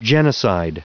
Prononciation du mot genocide en anglais (fichier audio)
Prononciation du mot : genocide